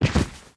Sound / sound / monster / wolf / drop_1_1.wav